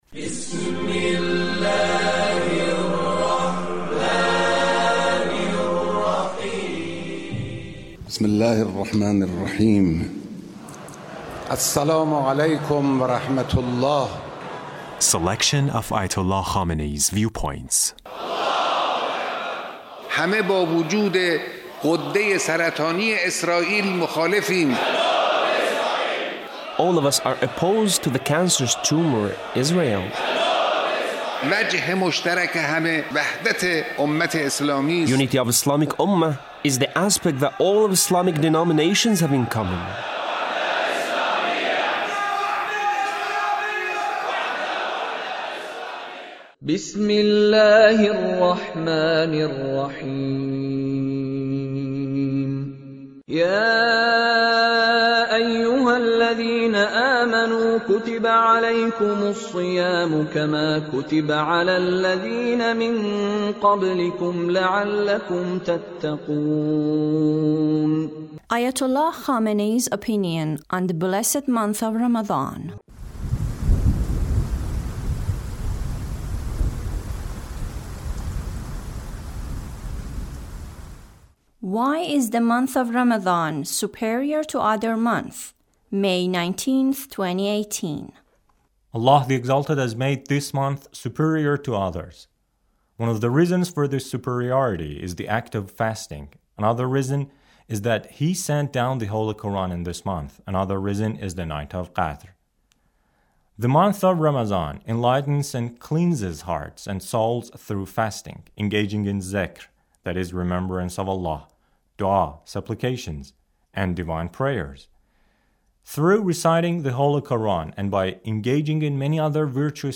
Leader's speech (67)